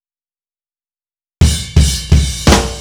Indie Pop Beat Intro 04.wav